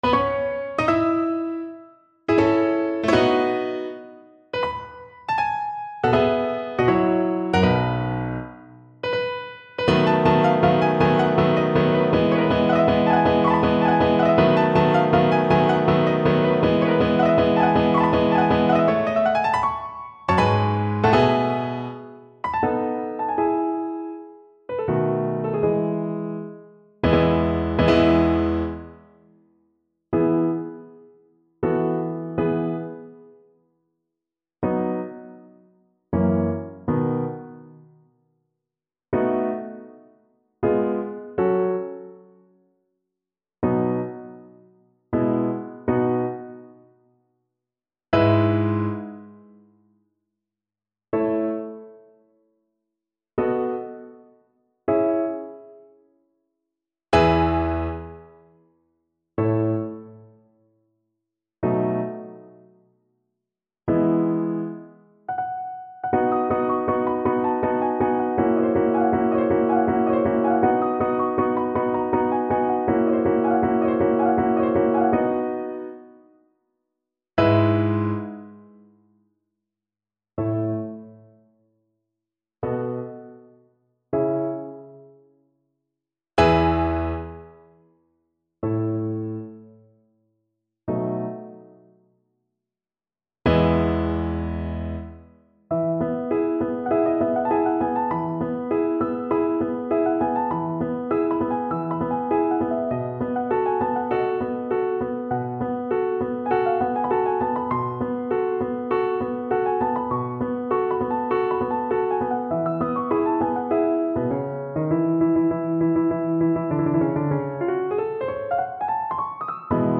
Classical Rossini, Giacchino Una voce poco fa from The Barber of Seville Violin version
Violin
3/4 (View more 3/4 Music)
E major (Sounding Pitch) (View more E major Music for Violin )
Allegro Moderato =80 (View more music marked Allegro)
Classical (View more Classical Violin Music)